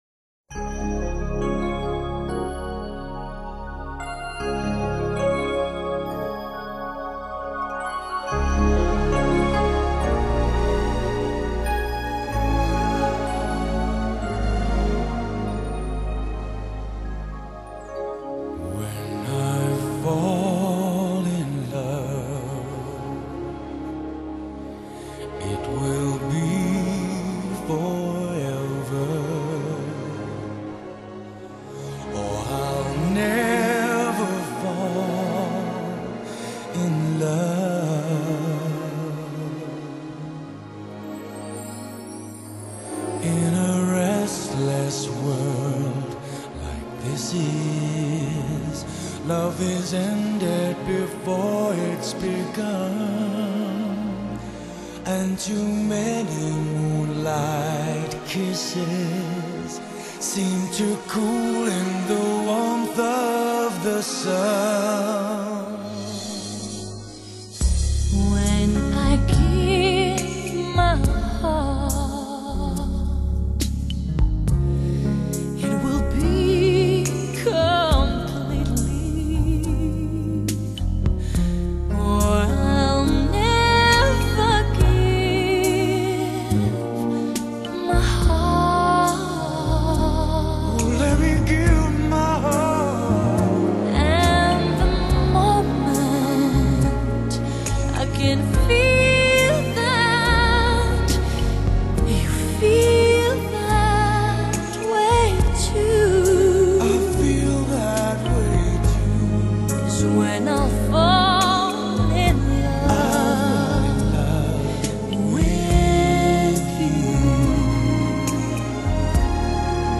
Genre: Pop, Ballads